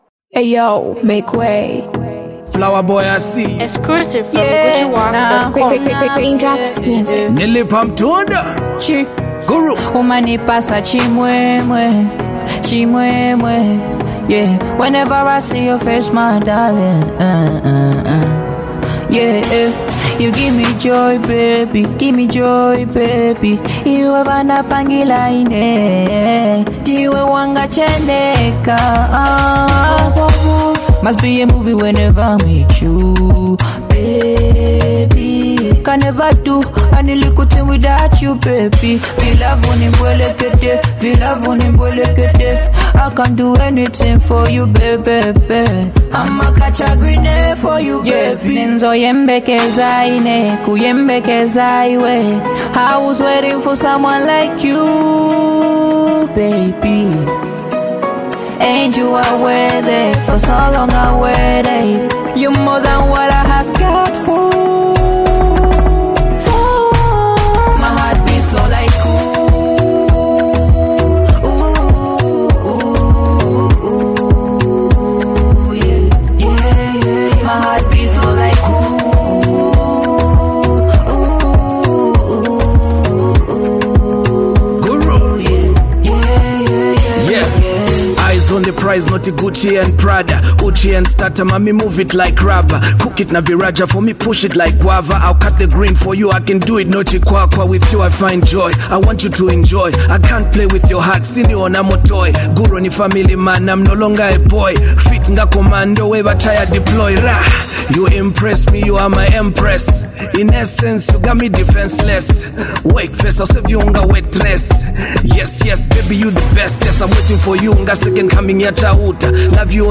Soulful Single
soulful track
blends meaningful lyrics with soothing melodies